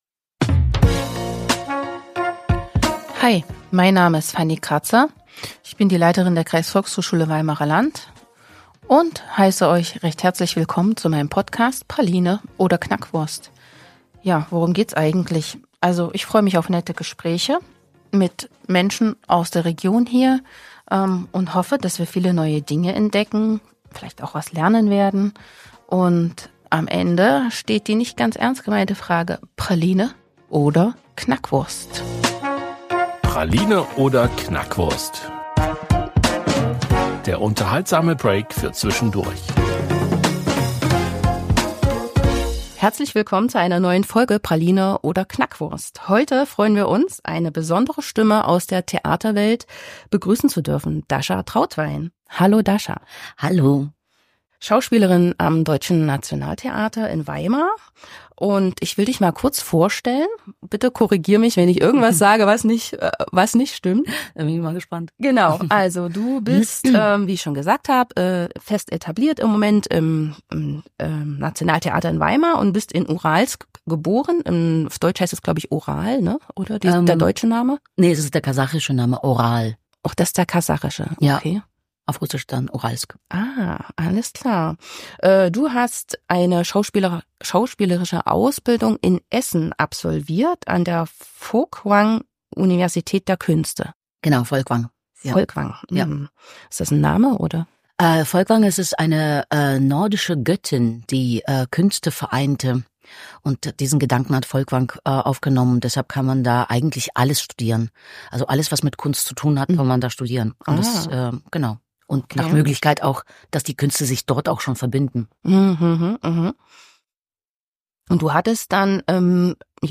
Mit "Praline oder Knackwurst" lockt die Kreisvolkshochschule Weimarer Land interessante Gäste vor das Mikrophon, die bei der KVHS eingecheckt haben oder mit denen die KVHS zusammenarbeitet.